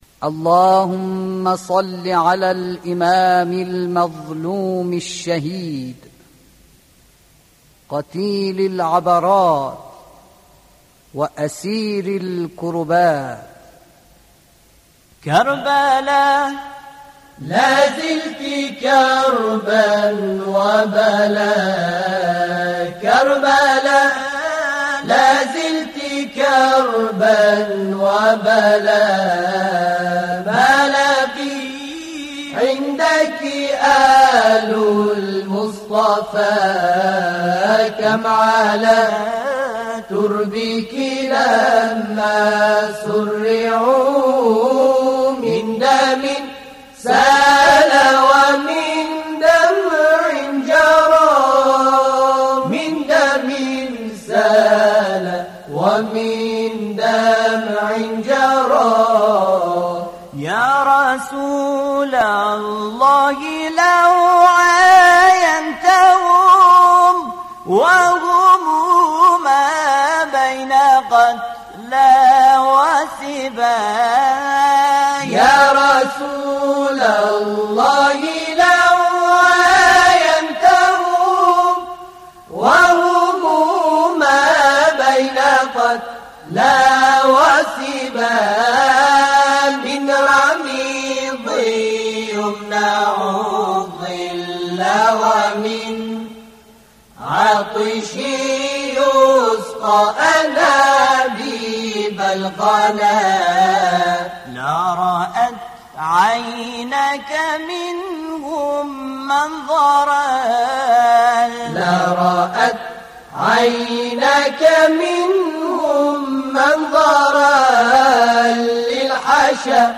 كربلاء لازلت ـ نغمة الصبا - لحفظ الملف في مجلد خاص اضغط بالزر الأيمن هنا ثم اختر (حفظ الهدف باسم - Save Target As) واختر المكان المناسب